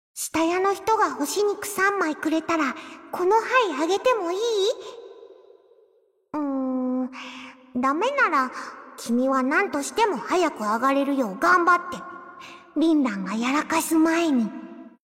声（CV） 豊崎愛生
初出は先月のCatChatで、舌足らずな食いしん坊の一面を覗かせていた。
一部ボイス（倍満／三倍満テンパイ）が修正された事件について。下家を「したや」と読んでいた。